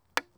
Rock Hit.wav